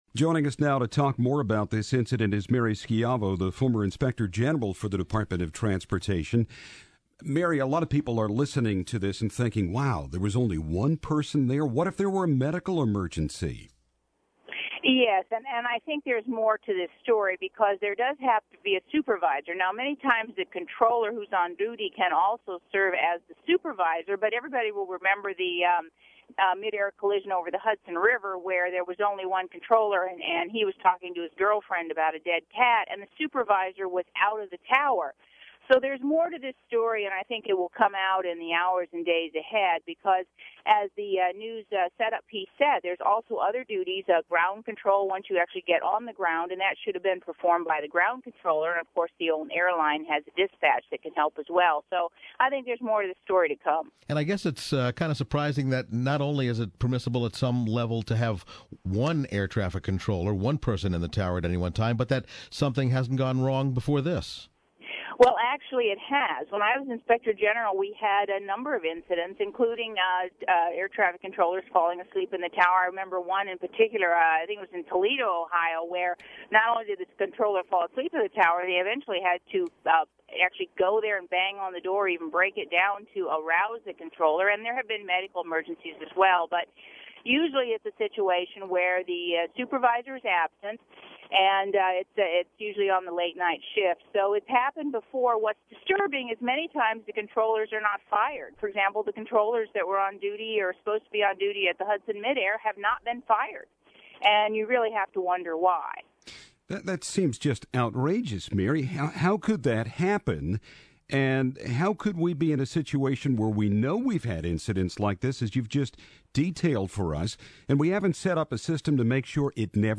in a radio interview with WTOP Radio, "A controller has many responsibilities, not the least of which is to guide you to land, but also to separate traffic and watch out for any problems or emergencies ... What needs to be done is obviously very strict rules that must be followed at all times.